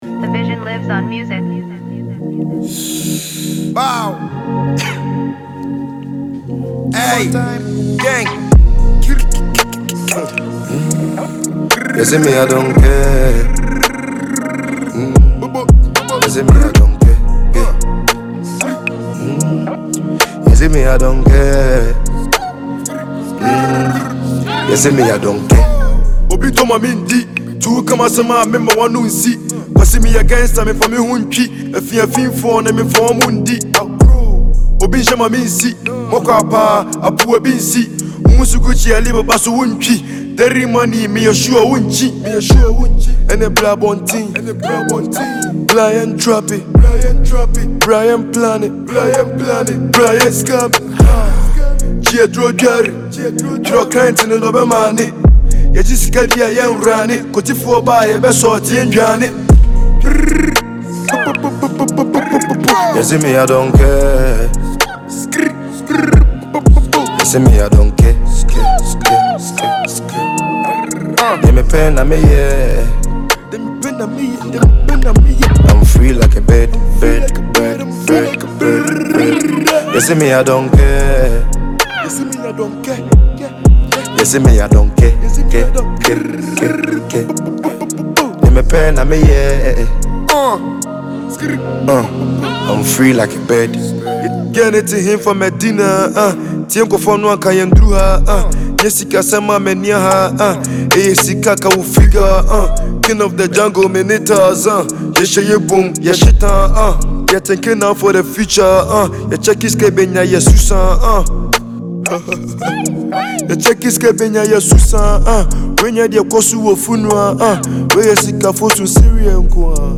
Ghanaian drill sensation
tough Asakaa anthem